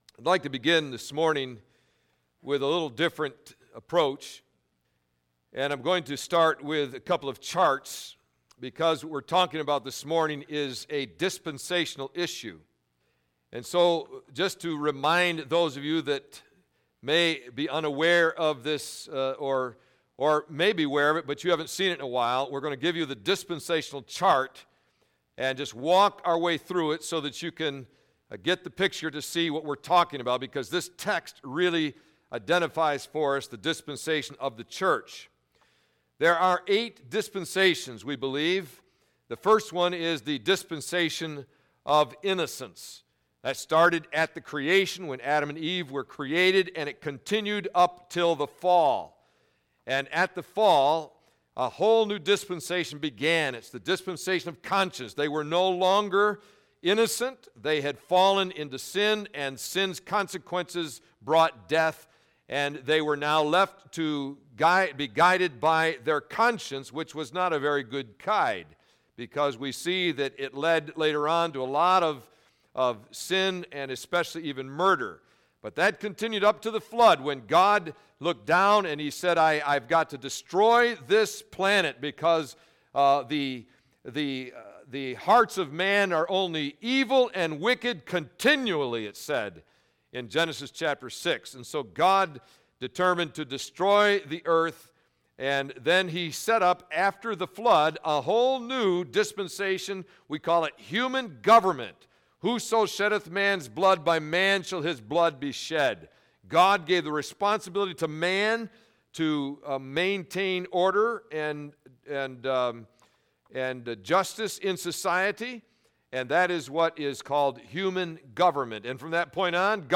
Sermon Archives The Church